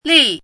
chinese-voice - 汉字语音库
li4.mp3